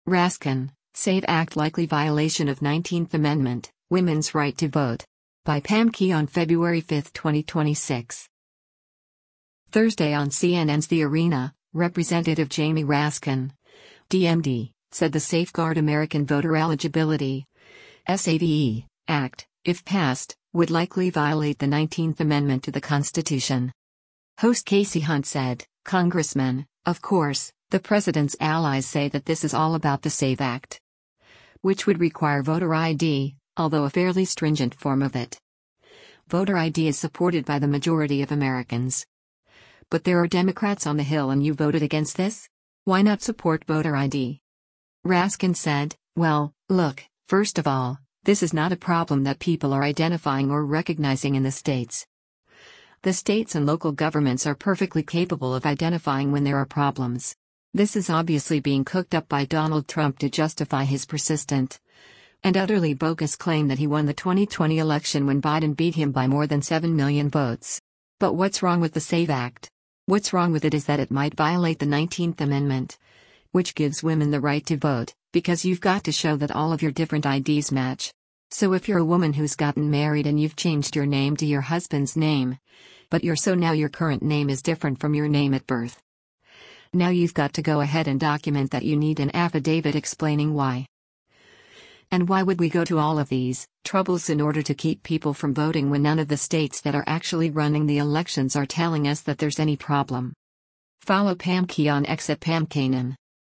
Thursday on CNN’s “The Arena,” Rep. Jamie Raskin (D-MD) said the Safeguard American Voter Eligibility (SAVE) Act, if passed, would likely violate the 19th Amendment to the Constitution.